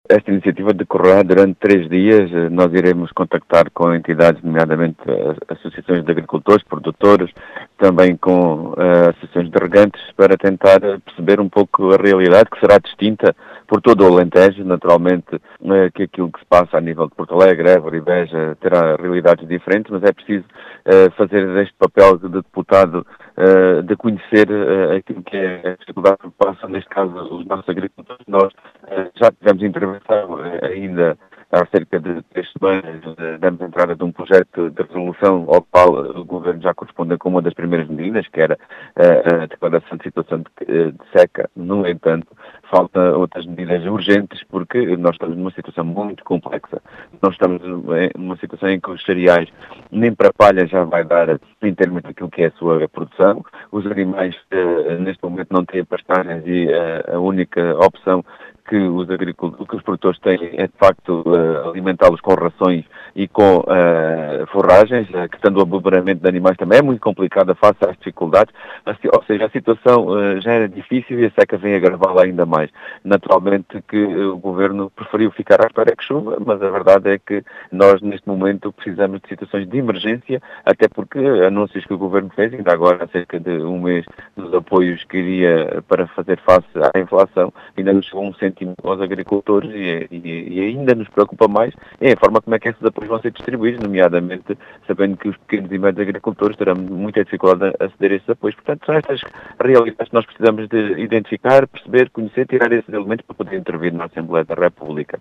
As explicações foram deixadas pelo deputado do PCP, João Dias, que quer conhecer a situação “complexa” da seca na região, e diz ser preciso medidas de “emergência”.